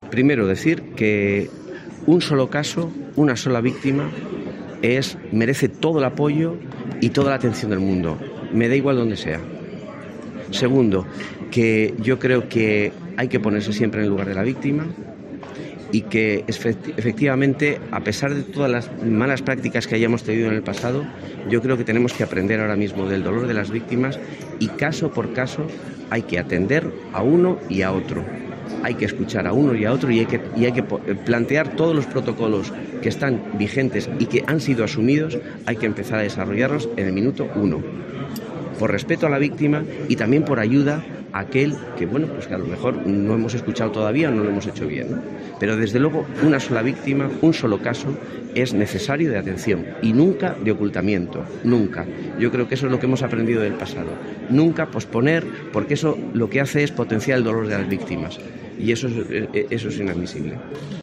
En una profunda entrevista en la que el arzobispo de Madrid ha abordado diferentes temas de actualidad, la periodista le ha explicado cómo a Roma "llegan todas las noticias de de todo lo que ocurre en todo el mundo" y cómo ha impactado la noticia del sacerdote detenido en Málaga.